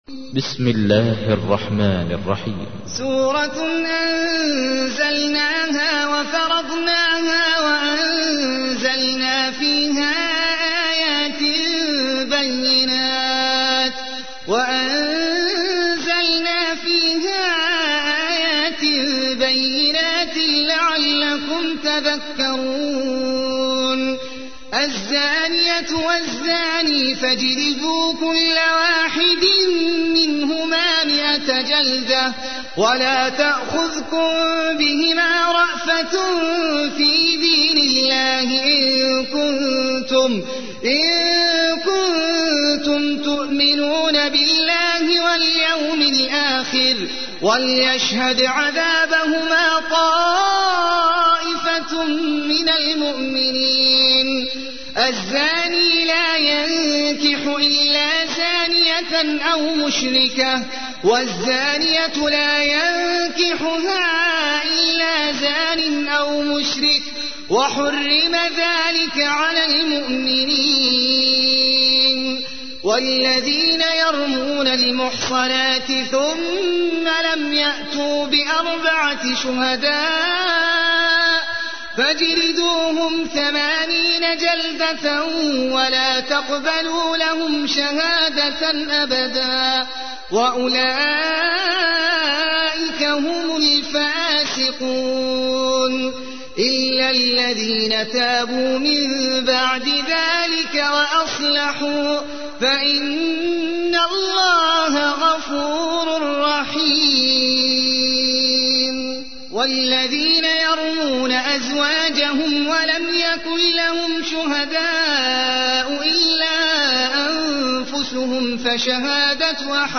تحميل : 24. سورة النور / القارئ احمد العجمي / القرآن الكريم / موقع يا حسين